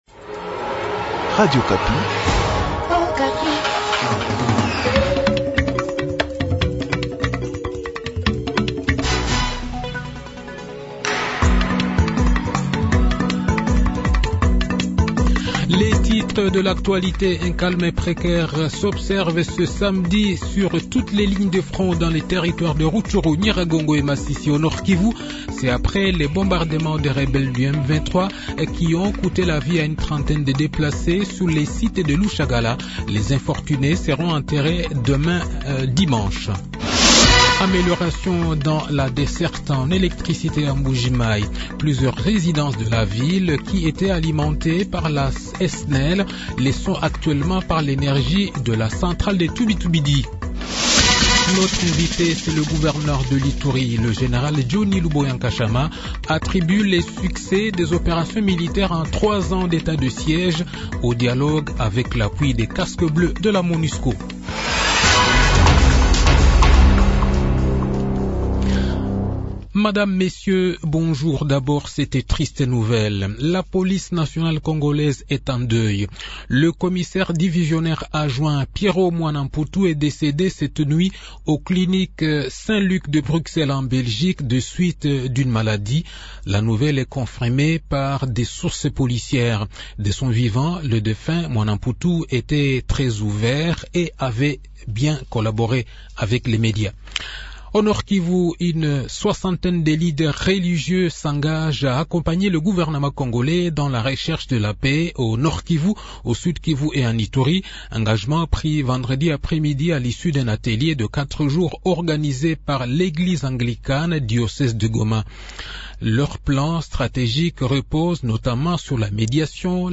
Journal de 15 h